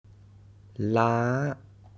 ToneMidLowFallingHighRising
Phoneticlaalàalâaláalǎa